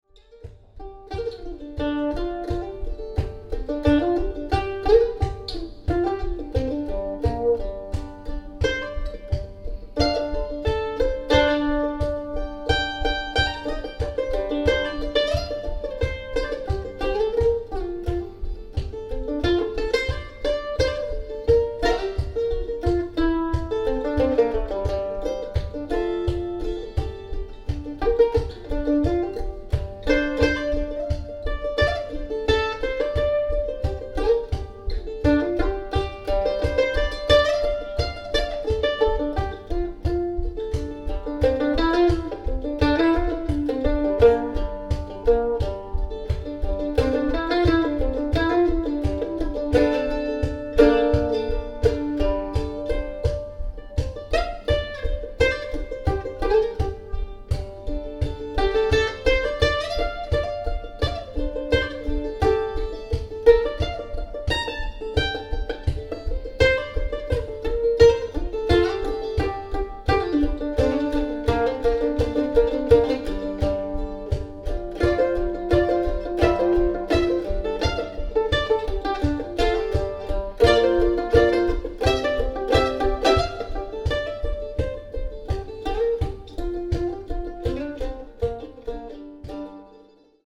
Mp3 Sound Effect Get ready for a raw, stomping journey. 🎶 Eastern March
improvised mandolin piece
recorded live in Missoula, Montana
With haunting eastern motifs and a pulse that hits like boots on dusty ground, this track rumbles with energy—so much so, the iPhone capturing it literally shakes with every stomp. Unfiltered. Unrehearsed. Just one man, a mandolin, and the spirit of the moment.